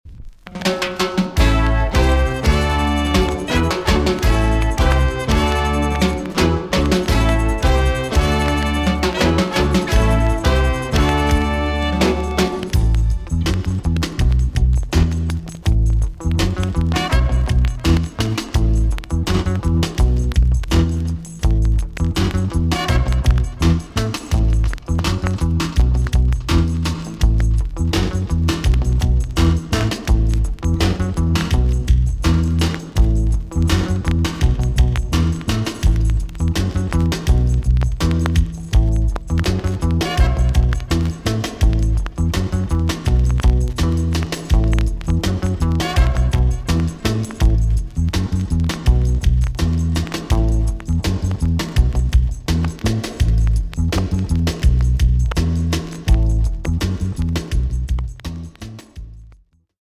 B.SIDE Version
VG+ 少し軽いチリノイズが入ります。